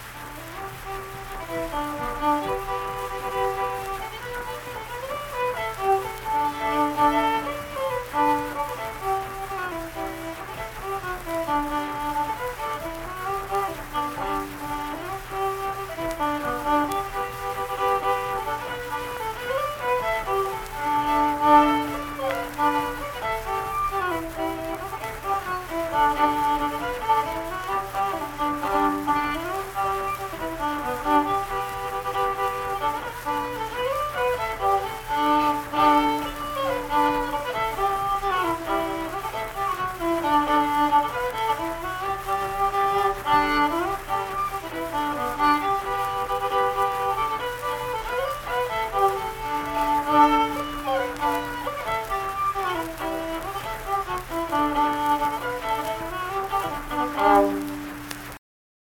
Coming Around The Mountain - West Virginia Folk Music | WVU Libraries
Unaccompanied fiddle music
Verse-refrain 4(1).
Instrumental Music
Fiddle
Harrison County (W. Va.)